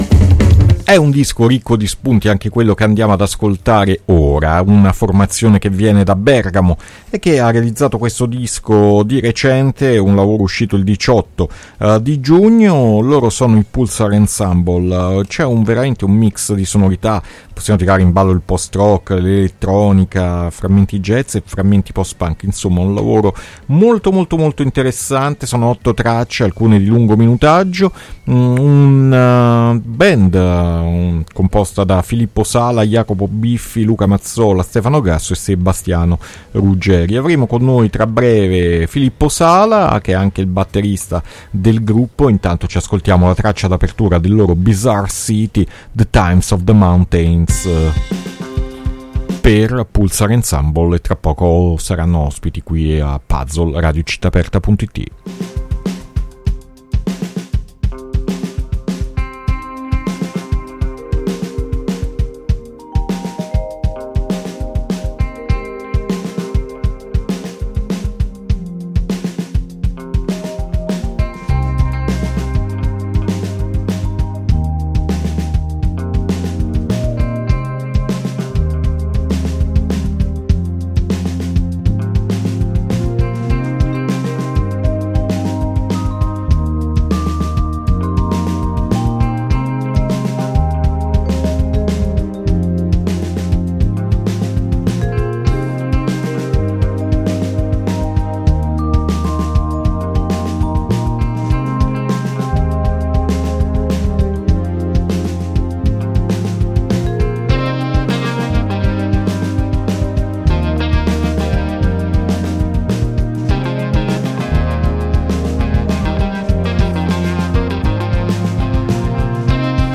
Intervista Pulsar Ensemble a Puzzle 5-7-2021